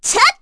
Chrisha-Vox_Attack1_kr.wav